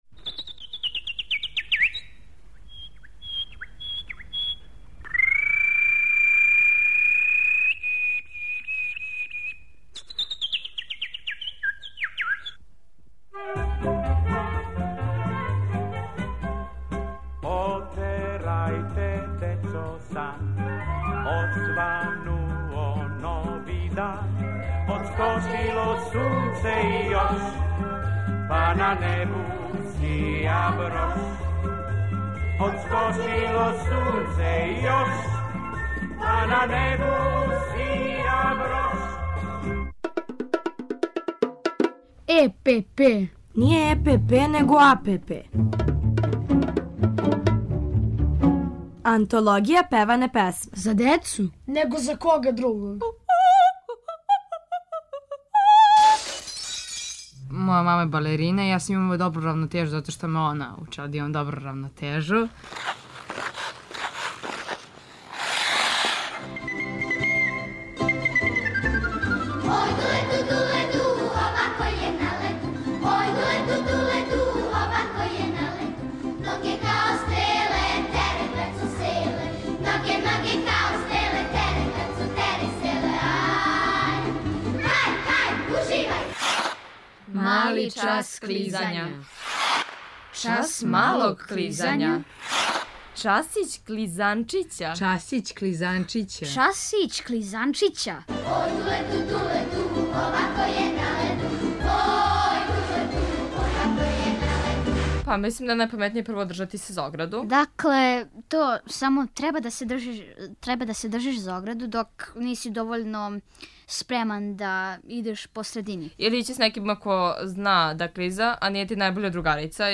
У серијалу Антологија певане песме, колибри пева на леду, а дечија драмска група Радио Београда држи час клизања!